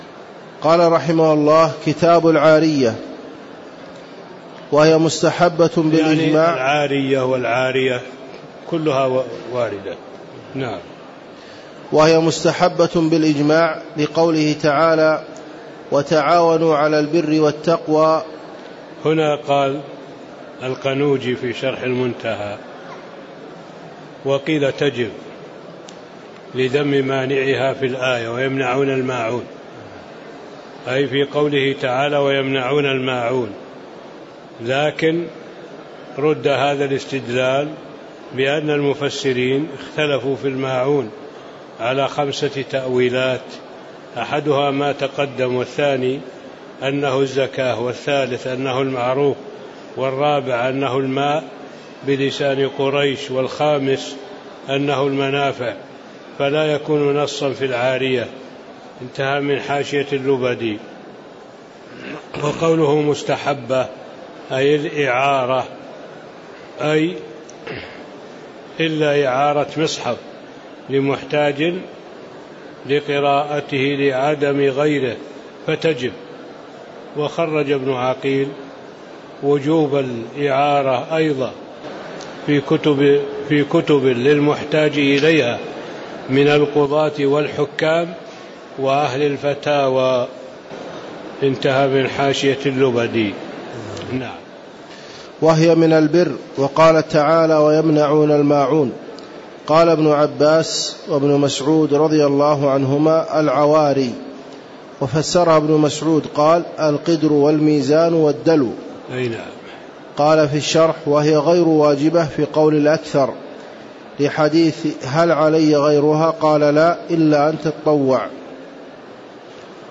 تاريخ النشر ١٠ صفر ١٤٣٧ هـ المكان: المسجد النبوي الشيخ